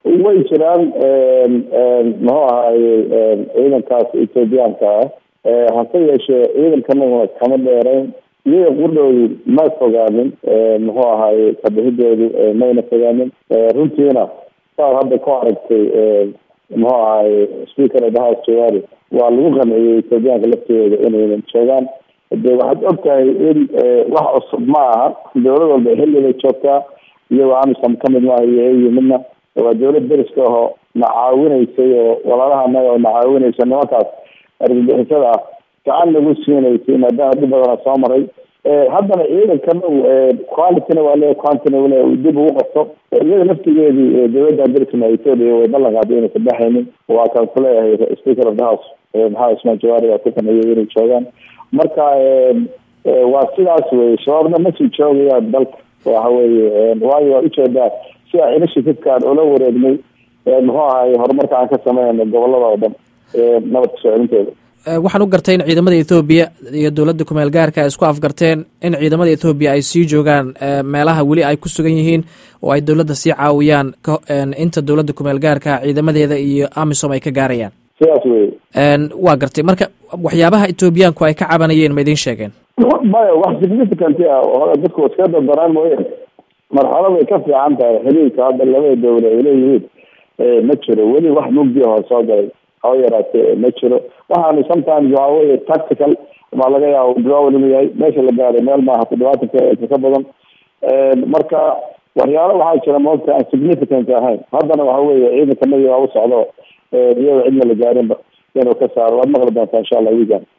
Dhageyso Wareysiga wasiirka